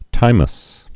(tīməs)